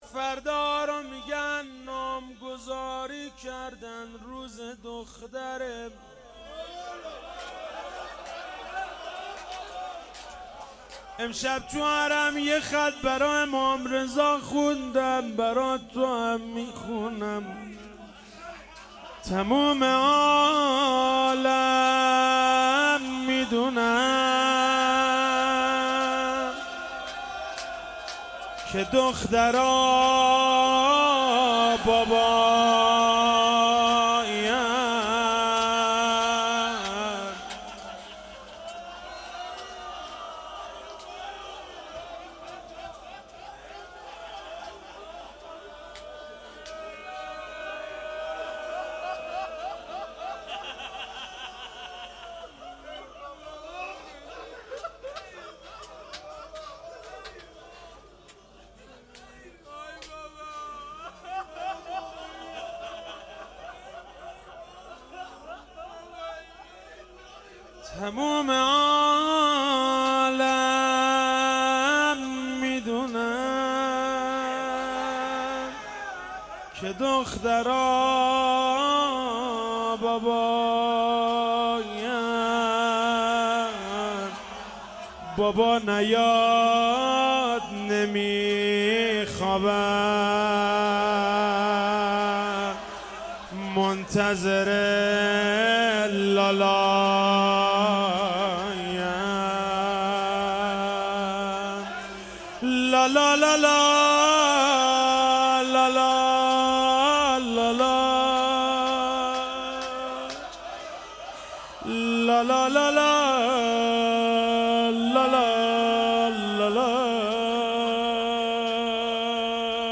روضه حضرت رقیه سلام الله علیها